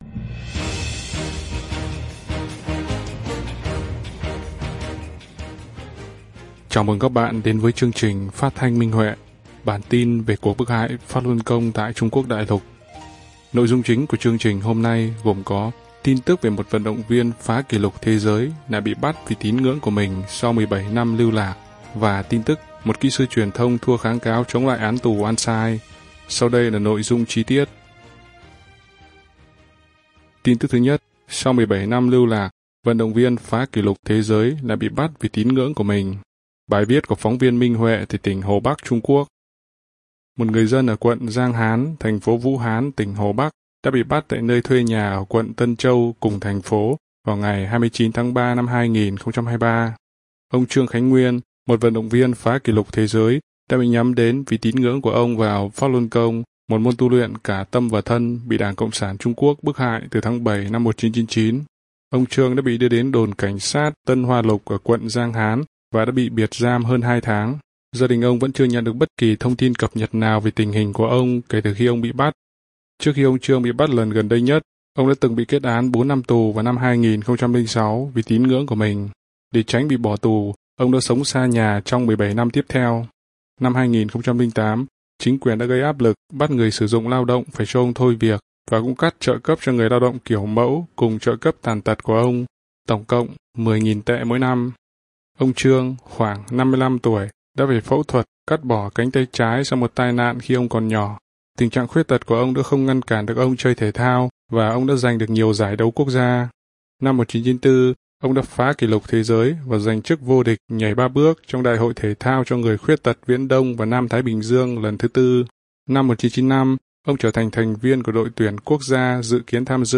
Chương trình phát thanh số 19: Tin tức Pháp Luân Đại Pháp tại Đại Lục – Ngày 21/6/2023